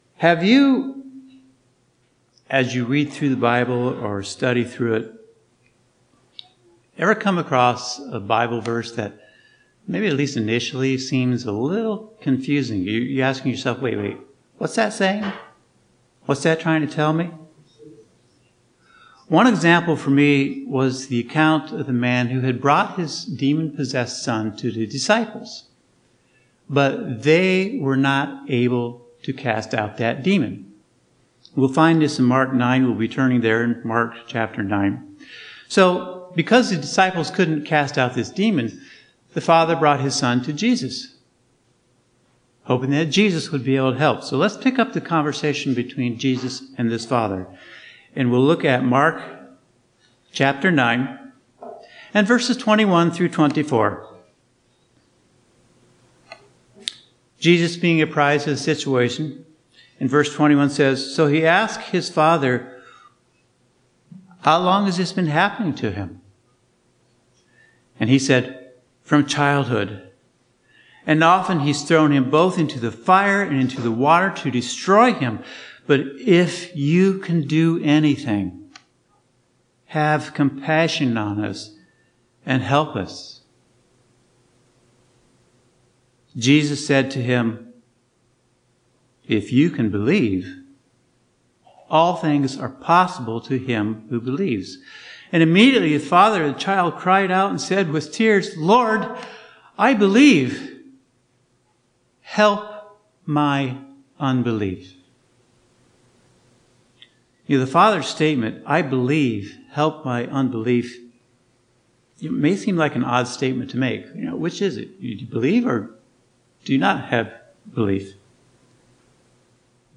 Sermons
Given in Northwest Indiana